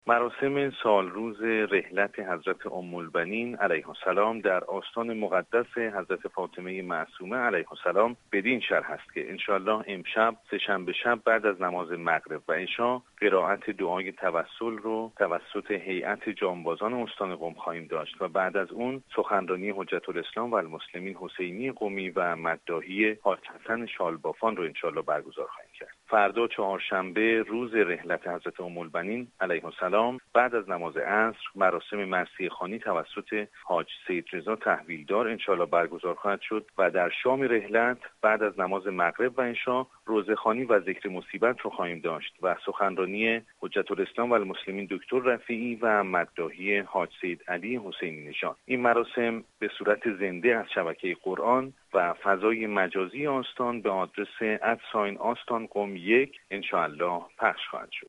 در گفتگو با خبر رادیو زیارت گفت